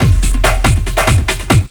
LOOP48--01-L.wav